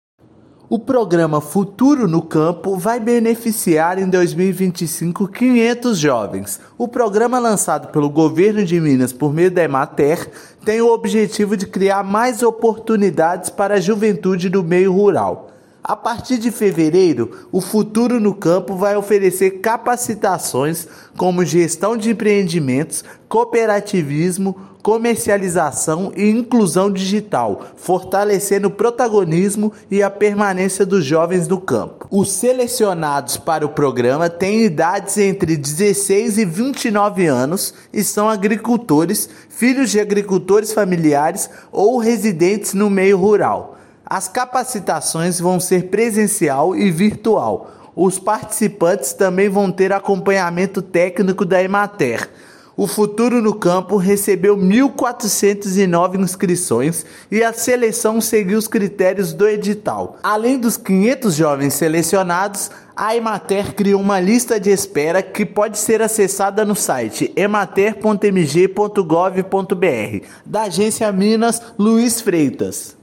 Programa Futuro no Campo vai beneficiar 500 jovens, com qualificação e ações de fomento. Ouça matéria de rádio.
Rádio_Matéria_Futuro_no_Campo.mp3